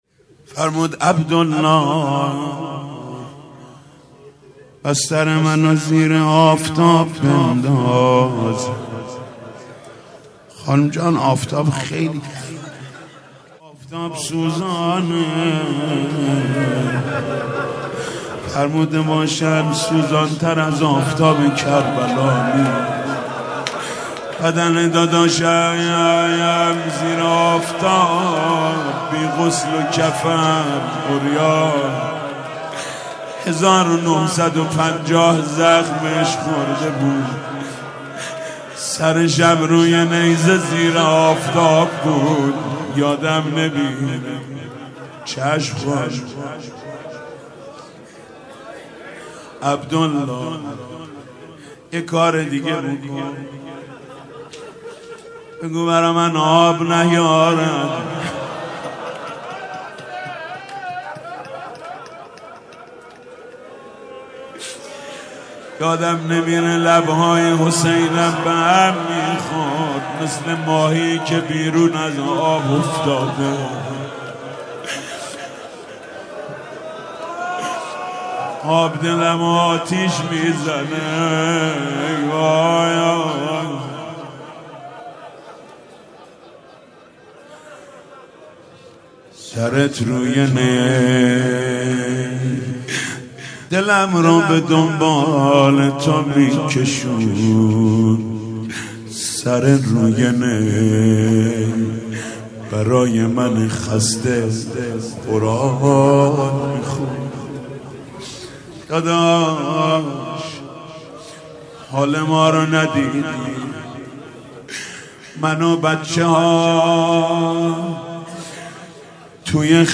روضه
روضه و ذکر